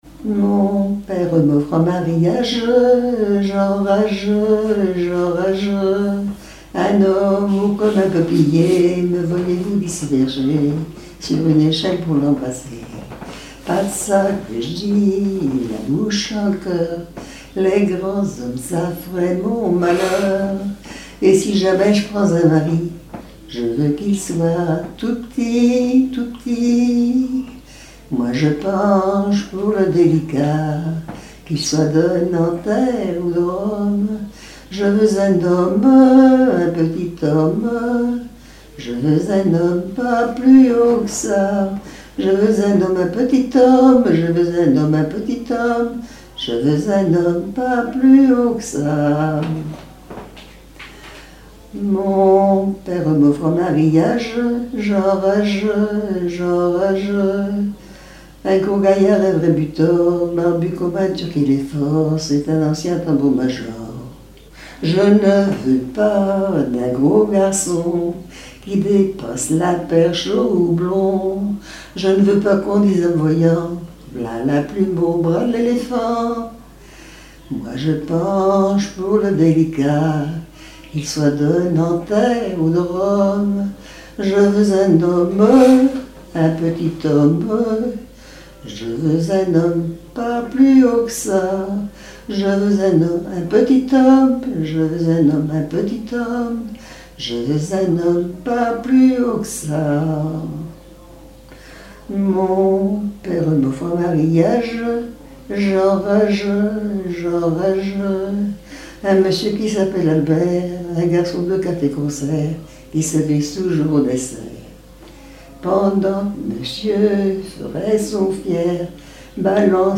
Mémoires et Patrimoines vivants - RaddO est une base de données d'archives iconographiques et sonores.
Genre strophique
Catégorie Pièce musicale inédite